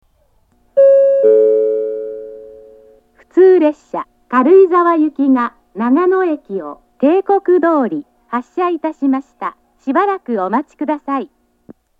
１番線長野駅発車案内放送 普通軽井沢行の放送です。
列車が長野駅を発車すると流れます。